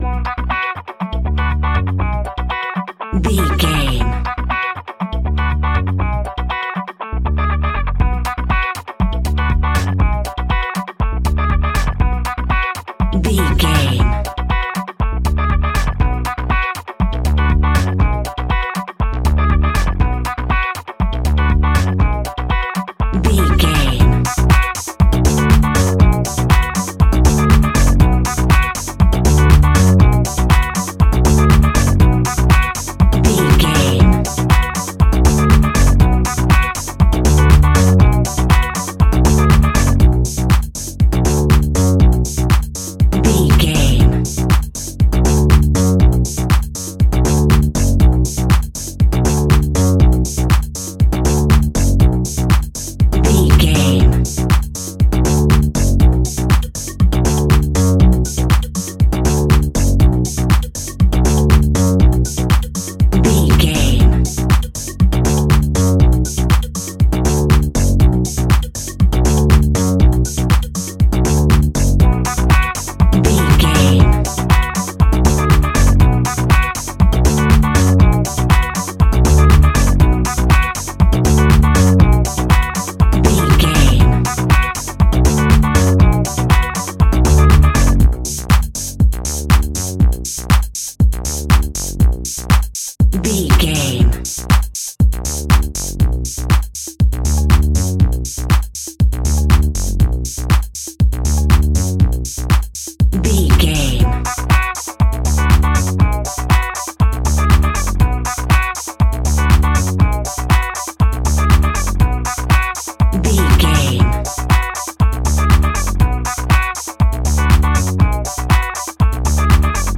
Aeolian/Minor
groovy
futuristic
uplifting
drums
electric guitar
bass guitar
funky house
disco house
electronic funk
synths
energetic
upbeat
synth leads
Synth Pads
synth bass
drum machines